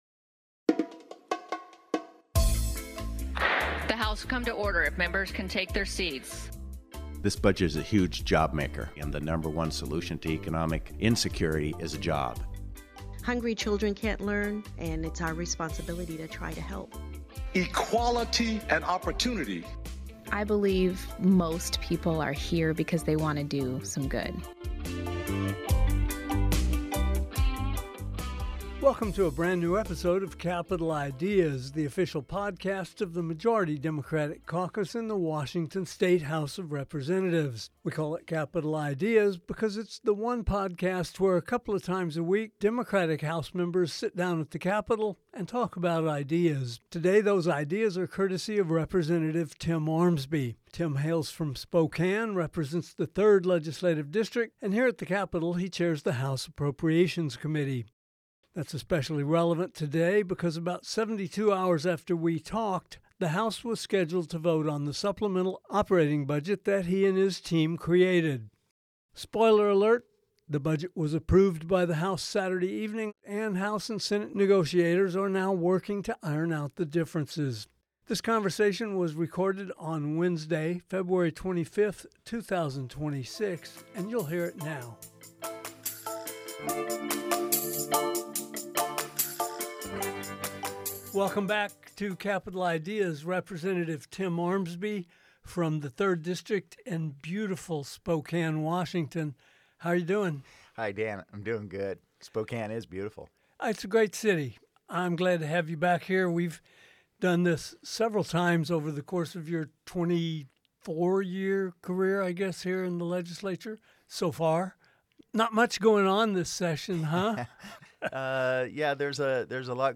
House Appropriations Chair Timm Ormsby makes one of his semi-regular appearances on Capitol Ideas today. With the supplemental operating budget in the works, this is a timely conversation.